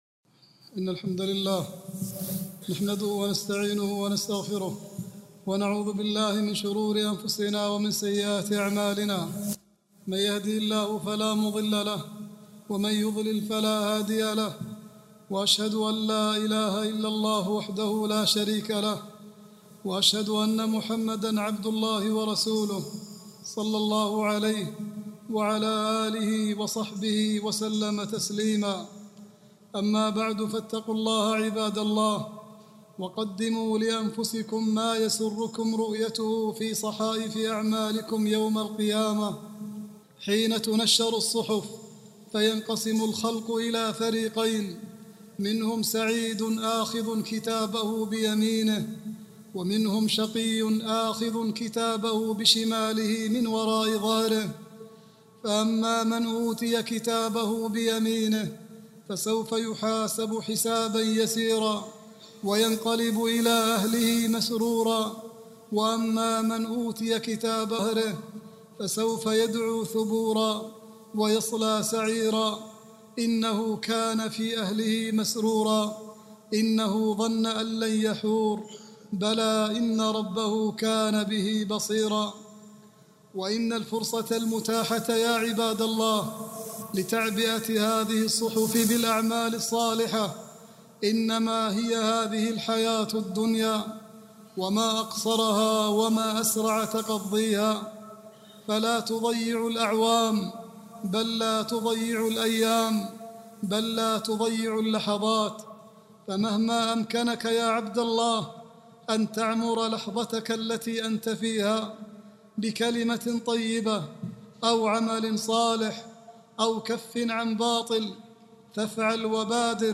العنوان : الحث على الصوم في شعبان والتحذير من بدعة الاحتفال بليلة المعراج خطبة
khutbah-27-7-39.mp3